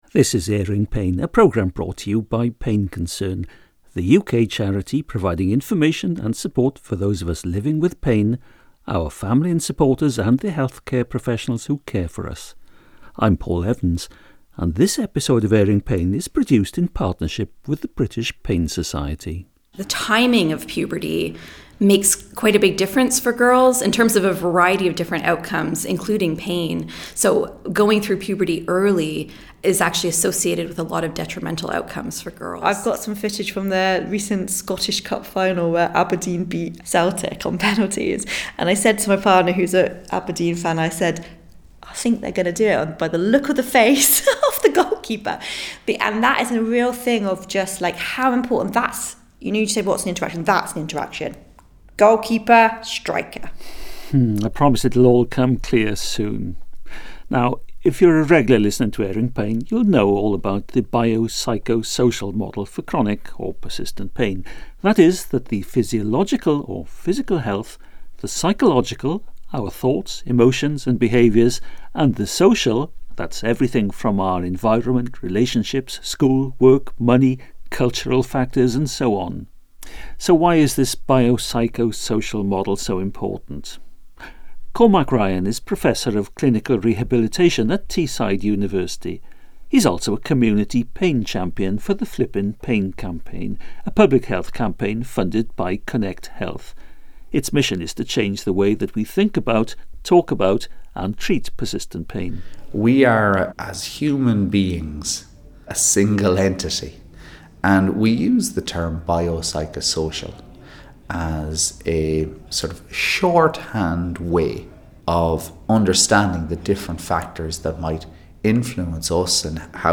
Thanks go to: The British Pain Society – the interviews in this episode were recorded at their 2025 Annual Scientific Meeting.